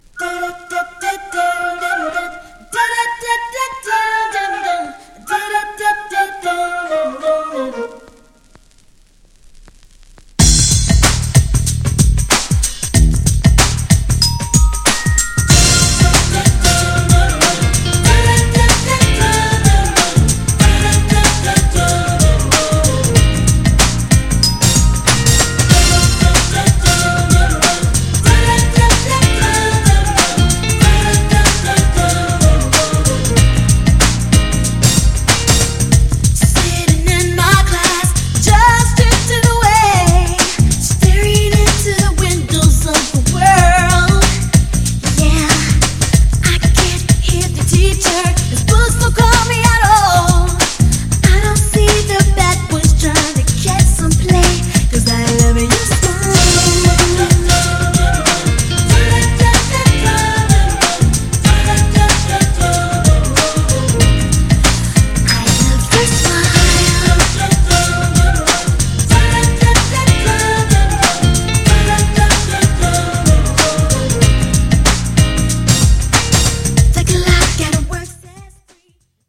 GENRE R&B
BPM 91〜95BPM
# 90sポップ
# キャッチーなR&B # 女性VOCAL_R&B